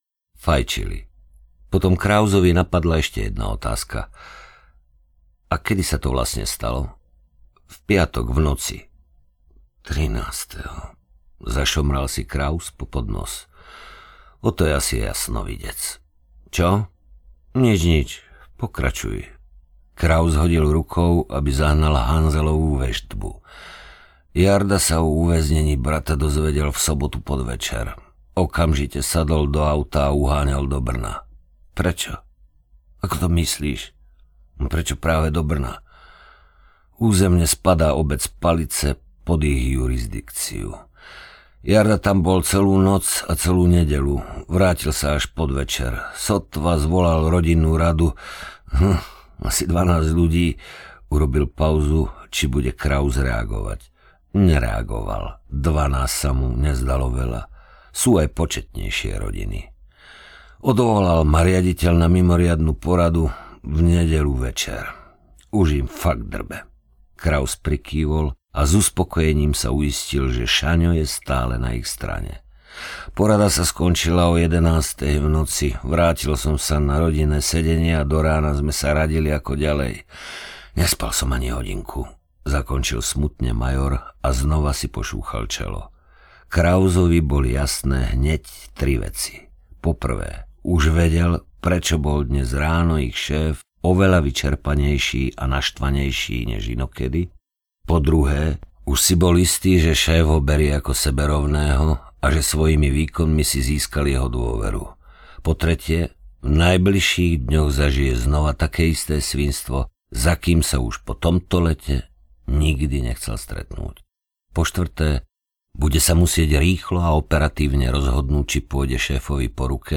Krv nie je voda audiokniha
Ukázka z knihy